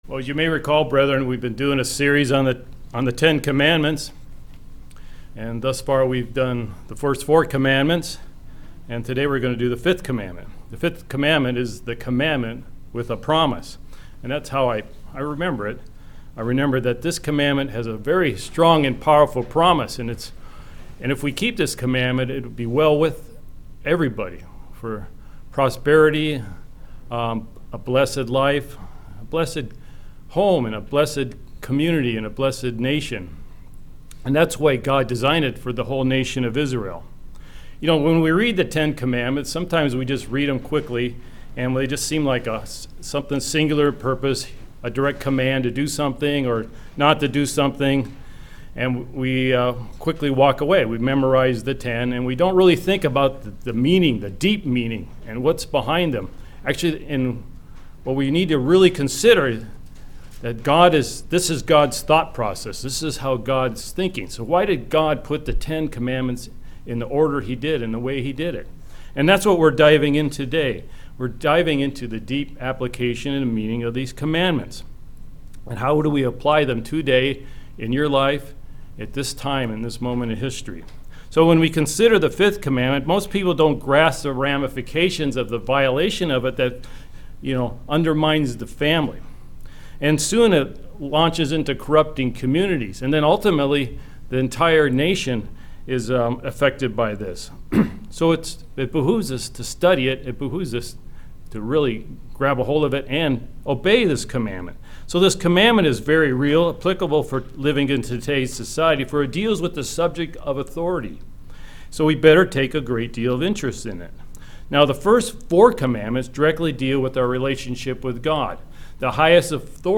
Sermons
Given in Kennewick, WA Chewelah, WA Spokane, WA